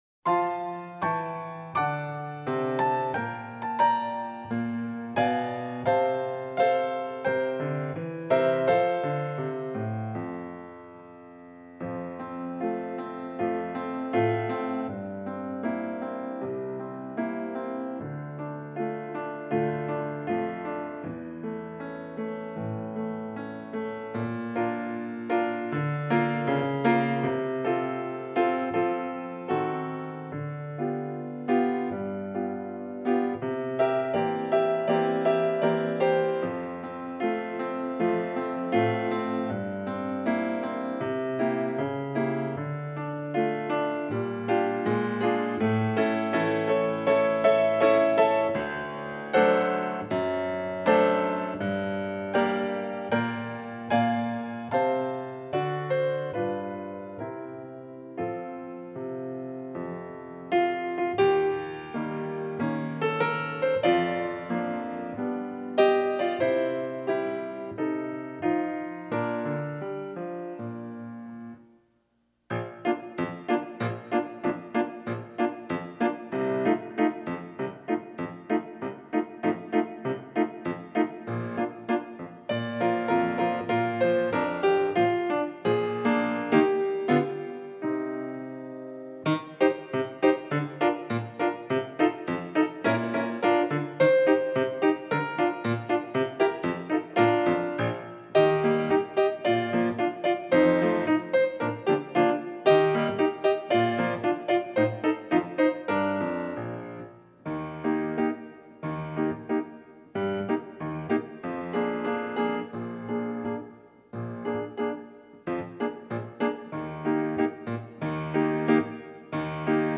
Trombone Solo Piano Only – Performance Tempo